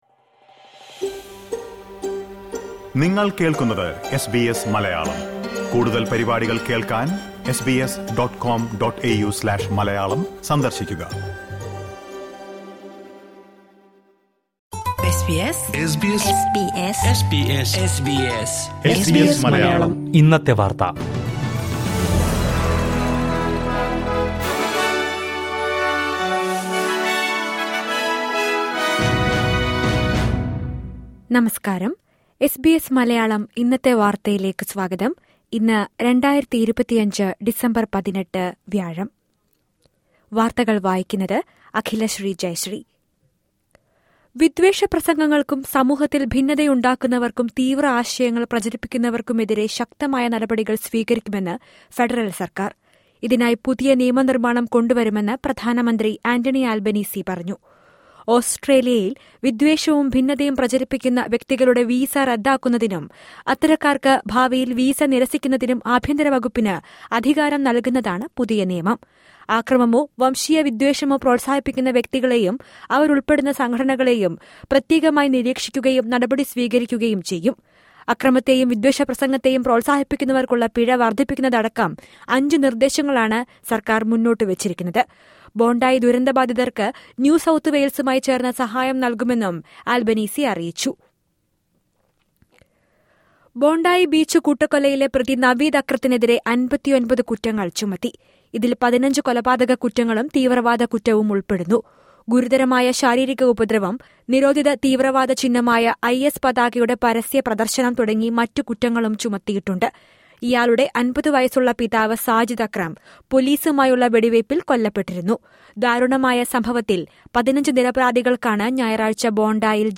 2025 ഡിസംബർ 18ലെ ഓസ്ട്രേലിയയിലെ ഏറ്റവും പ്രധാന വാർത്തകൾ കേൾക്കാം.